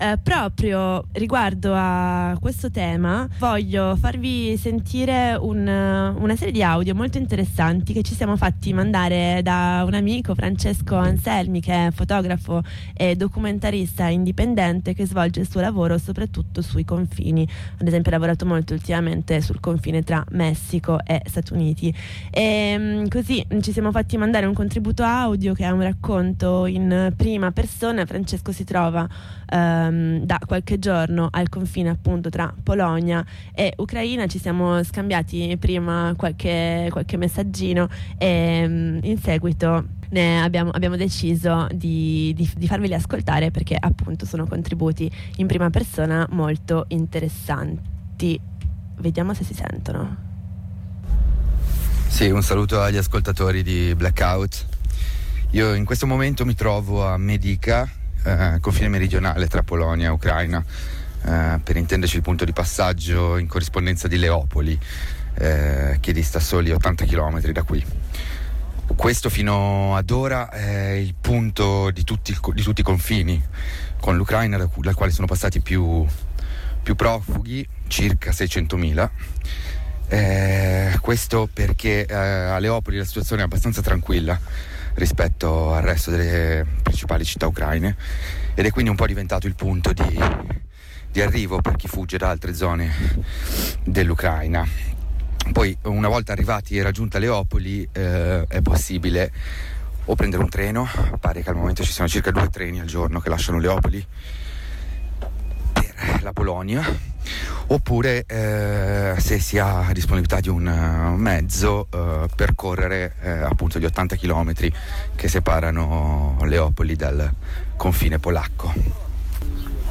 Gli abbiamo chiesto di raccontarci la situazione dellə rifugiatə nel punto del confine maggiormente attraversato da chi scappa dai bombardamenti russi.